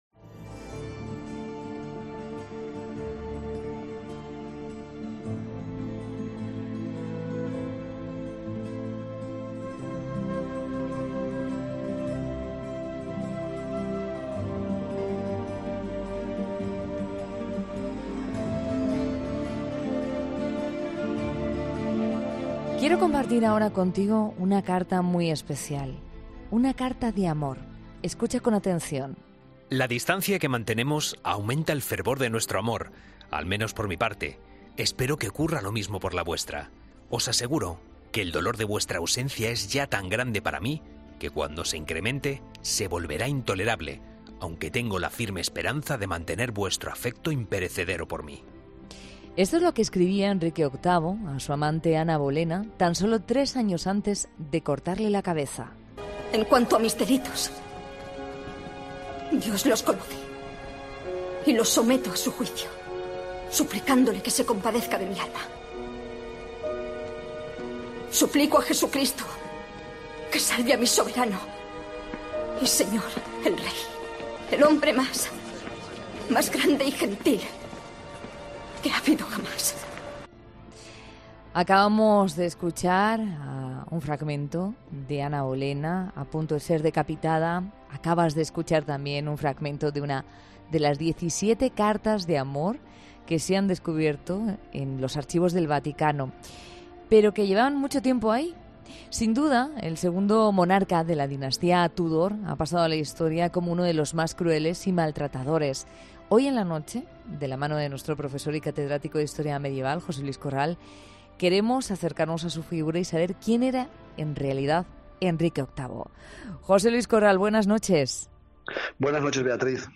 En La Noche de COPE , el historiador José Luis Corral nos ayuda a desvelar su figura.